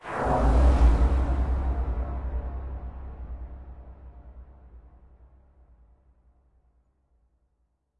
电影时刻 短暂而突然 " 暖吹 0 A 40z
描述：用合成器制作的深沉温暖的开放性打击。这样的声音可能对电影或游戏有用。
Tag: 柔软 扑扇 混响 电影 电影 温暖 黑暗 金属的 静音 合成的